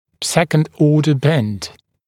[‘sekənd ‘ɔːdə bend][‘сэкэнд ‘о:дэ бэнд]изгиб второго порядка